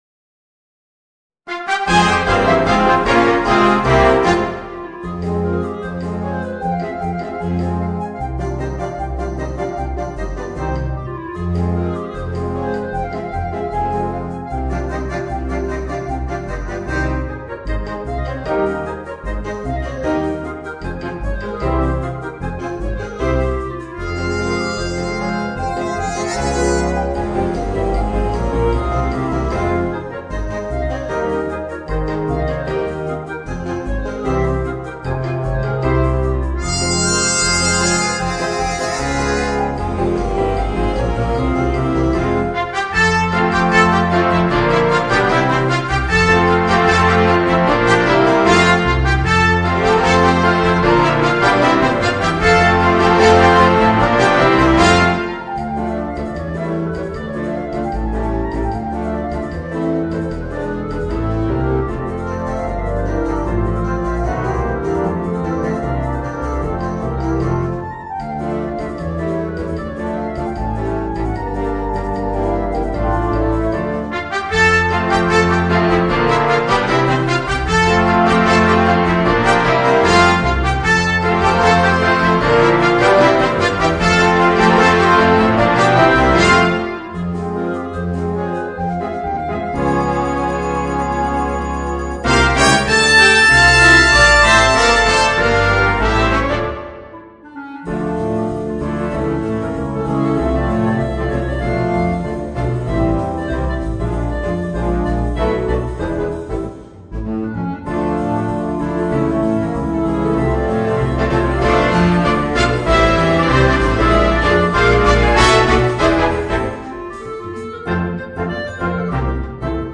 Voicing: Alto Saxophone and Concert Band